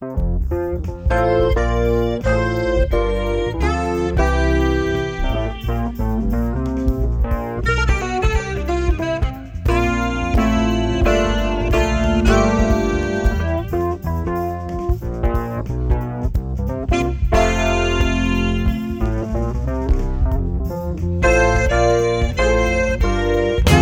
Так, я миксанул обе версии в противофазе. Барабаны почти полностью вычитаются (это говорит о практически полной идентичности), бочка с басом вычитаются с огромными потерями, а вот с клавишными и духовыми что-то не то - здесь или другой баланс, или другой тайминг, ил обработка.